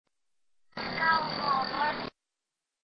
Two Class B EVP's were captured during this mini-investigation.
#2 Here is the Reverse EVP:
Cape_Cemetery_2_EVP_Reverse.mp3